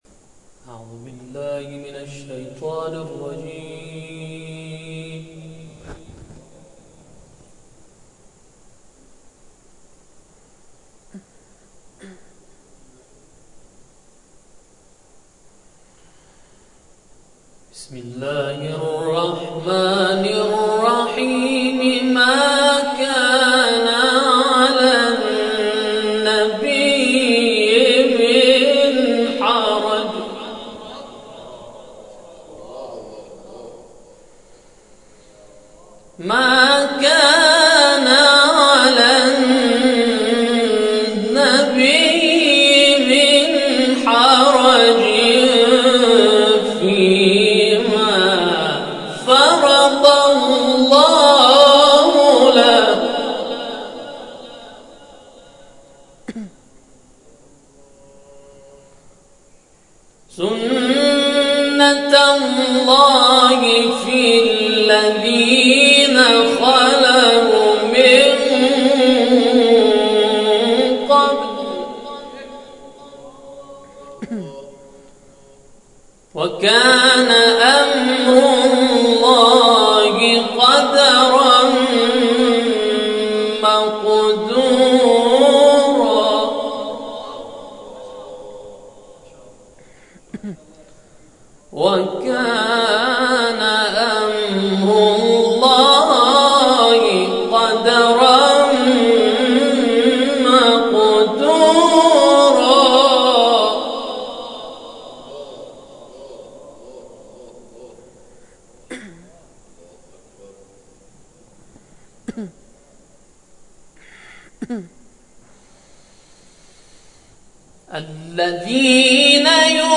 در ادامه گزارش تصویری و تلاوت های جلسه شب گذشته ارائه می‌شود.